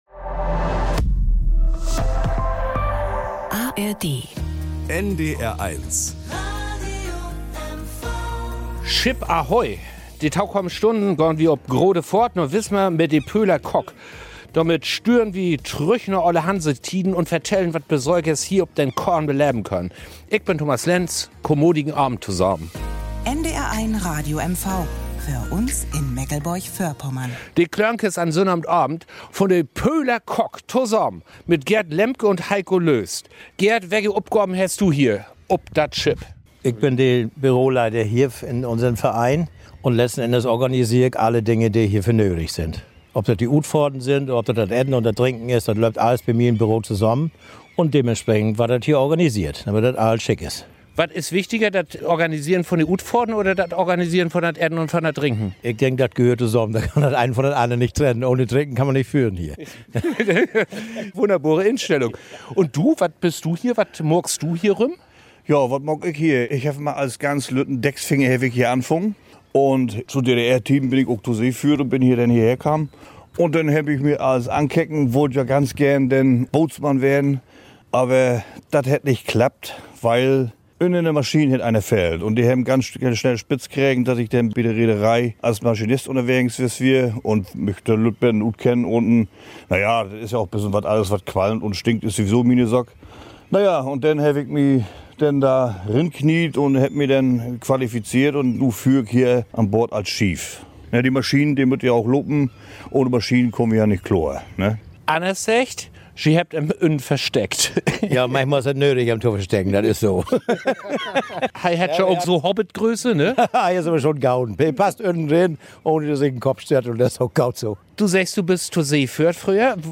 und zwar dort, wo sich die beiden am wohlsten fühlen: auf ihrer Kogge.